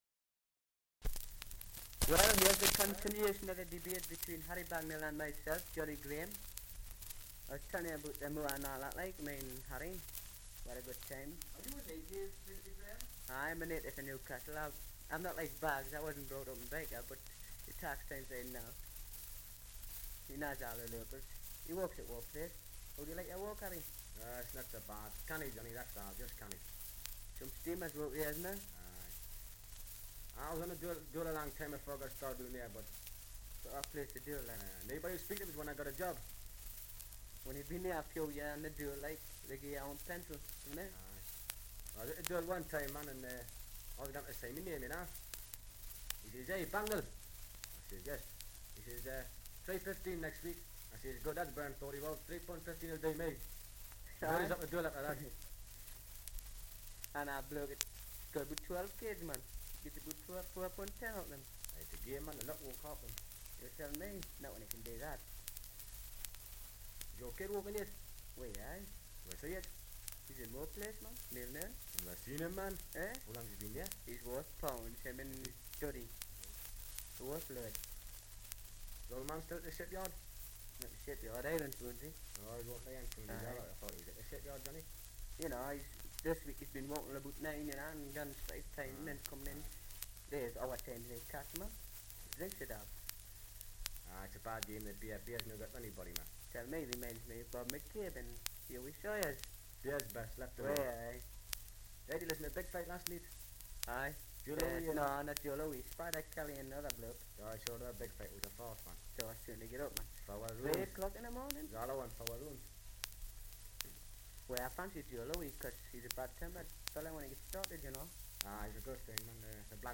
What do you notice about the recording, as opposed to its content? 78 r.p.m., cellulose nitrate on aluminium